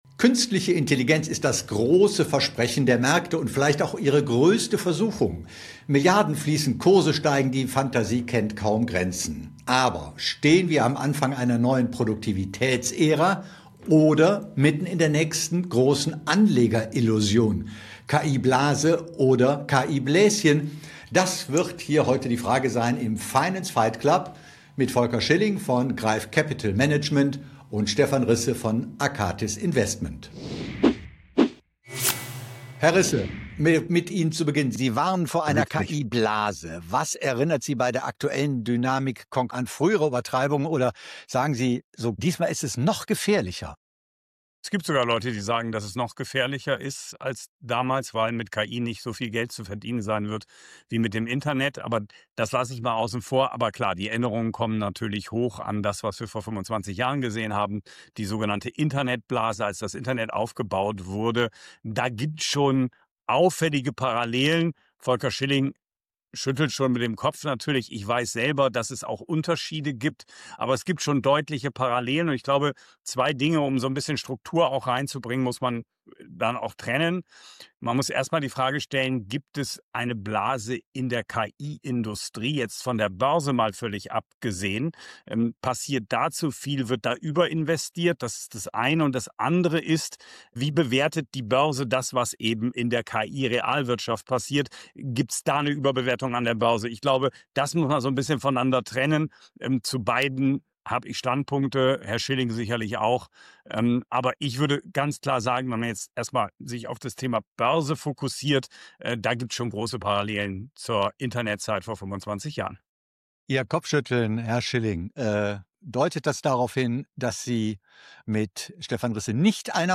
Im Finance Fight Club diskutieren zwei erfahrene Fondsmanager leidenschaftlich über Chancen, Risiken und historische Parallelen des KI-Booms.
Ein kontroverses Streitgespräch über Bewertungen, Cashflows, Nvidia, Diversifikation, ETFs – und die Frage, wie Anleger heute mit KI umgehen sollten.